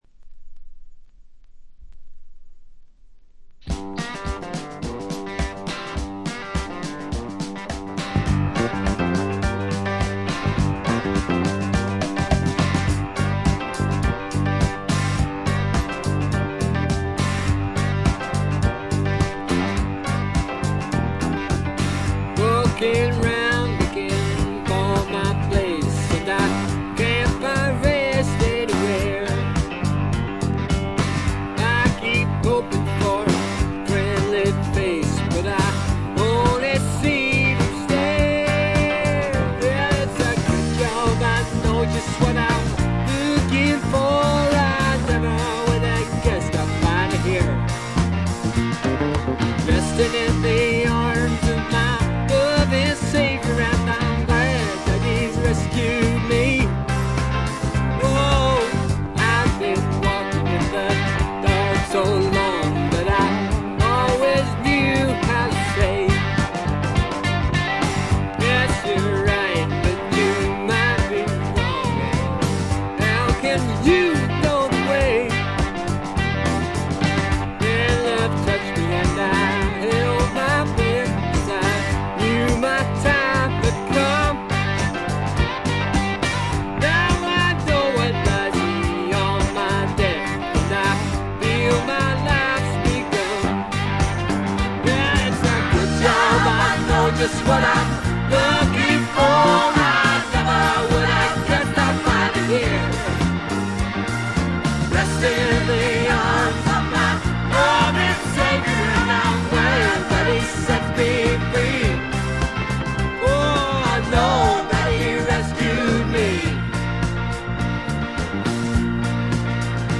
*** LP ： UK 1975
バックグラウンドノイズ、軽微なチリプチが出ていますが、特に気になるノイズはありません。
試聴曲は現品からの取り込み音源です。